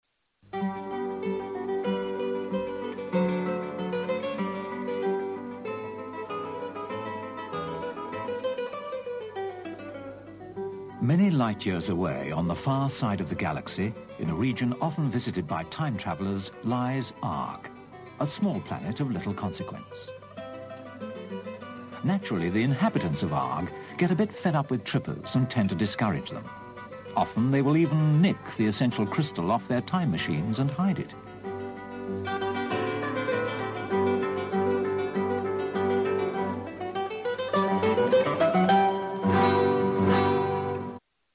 Theme tune